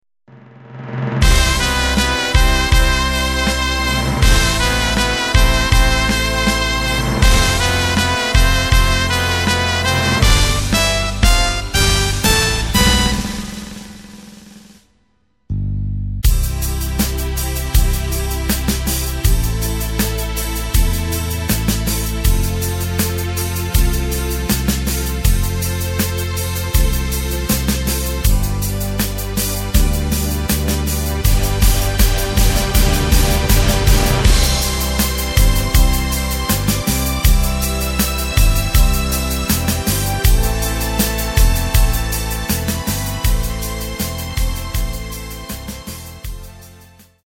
Takt:          4/4
Tempo:         80.00
Tonart:            C
Schlager/Oldie aus dem Jahr 2005!
Playback mp3 Demo